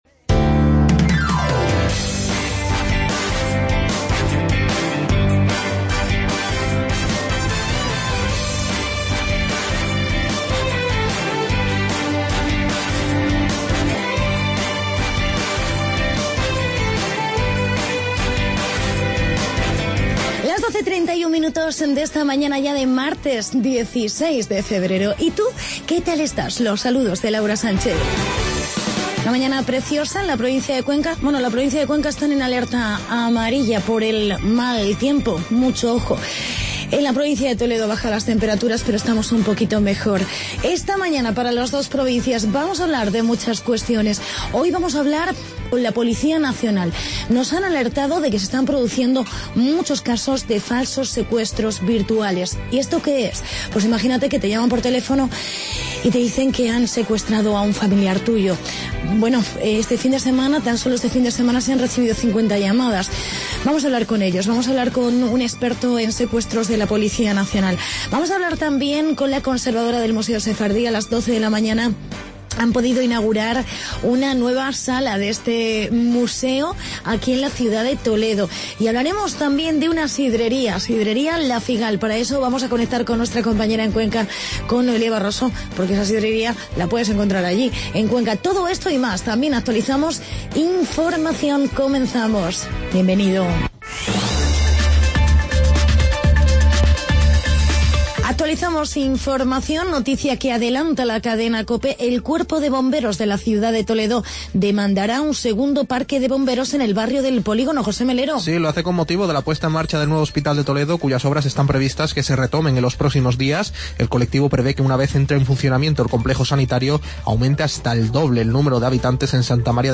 Entrevista con la Policía Nacional sobre falsos secuestros,